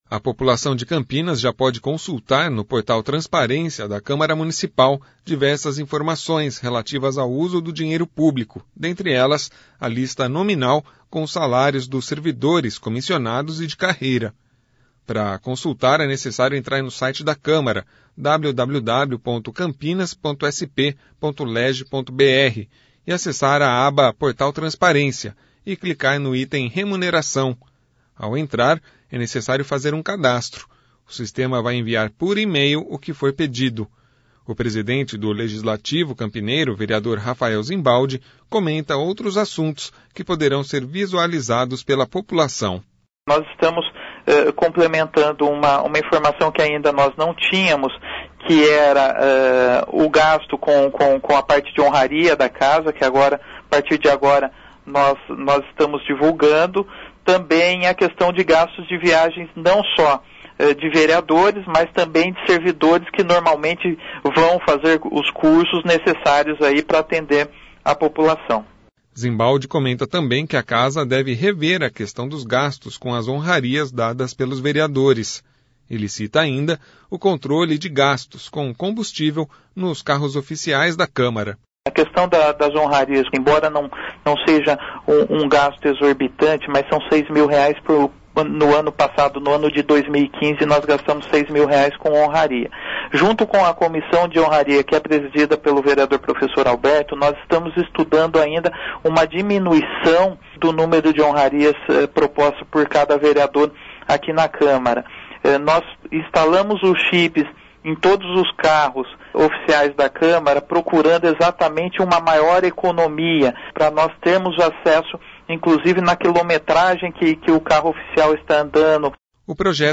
O presidente do legislativo campineiro, vereador Rafael Zimbaldi, comenta outros assuntos que poderão ser visualizados pela população.